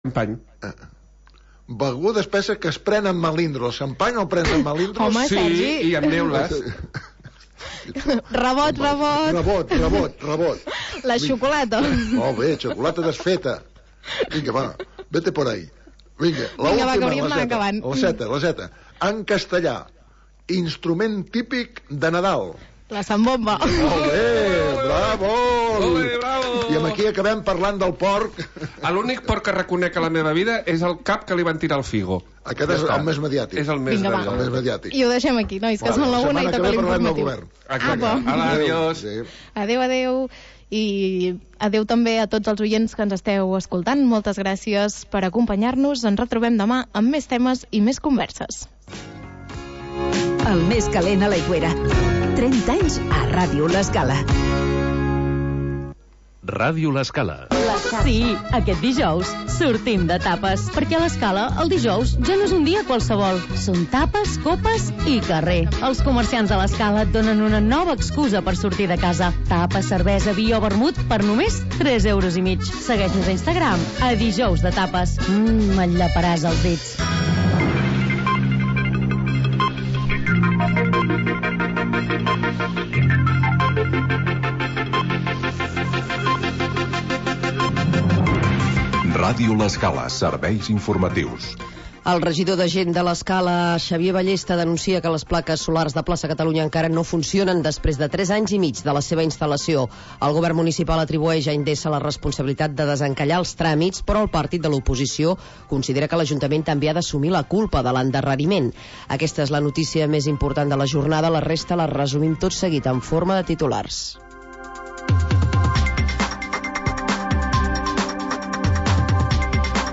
Noticiari d'informació local